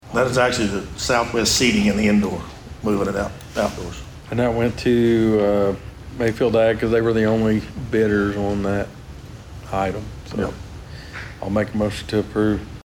At Monday's meeting, a bid was awarded to a company for just over $31,000 to move a section of the Osage County fairgrounds grandstands. Commissioners Anthony Hudson
and Steve Talburt go into more detail.